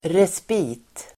Ladda ner uttalet
Uttal: [resp'i:t]